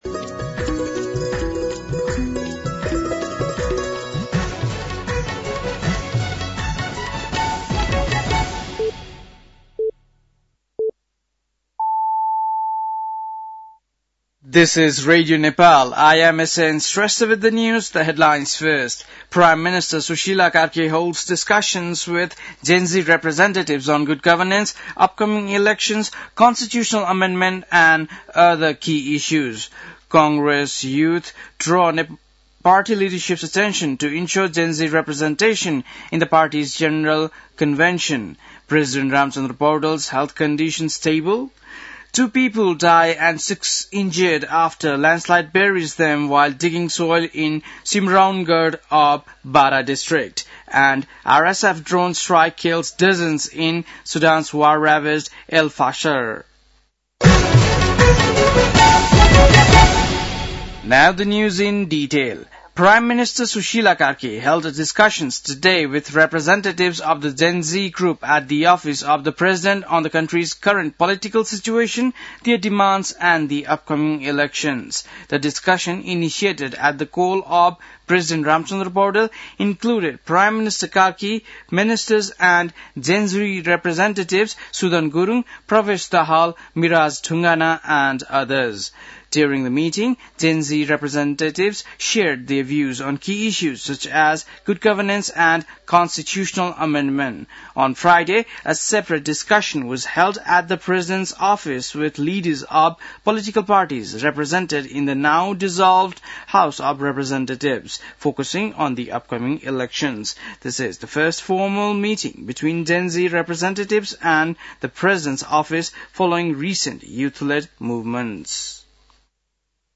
बेलुकी ८ बजेको अङ्ग्रेजी समाचार : २५ असोज , २०८२